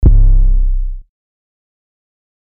(SXJ) 808 (1).wav